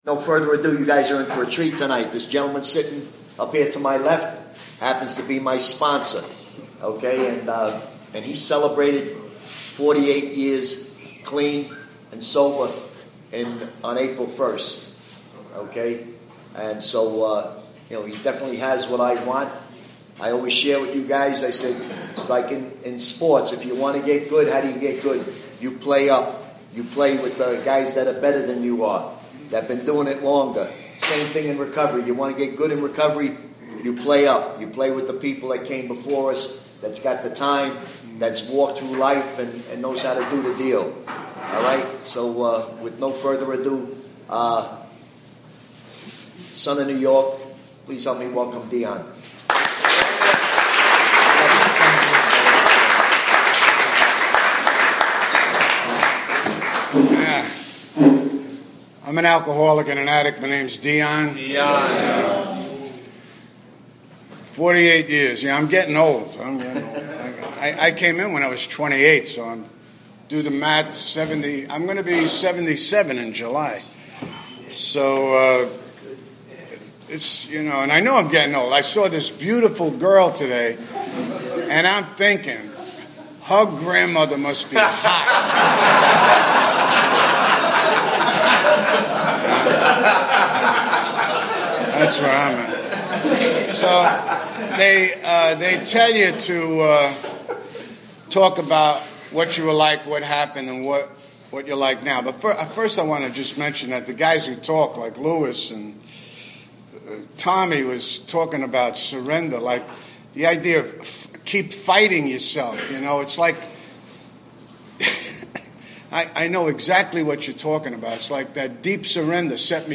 AA Speaker Recordings AA Step Series Recordings Book Study